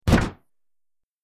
دانلود آهنگ تصادف 36 از افکت صوتی حمل و نقل
جلوه های صوتی
دانلود صدای تصادف 36 از ساعد نیوز با لینک مستقیم و کیفیت بالا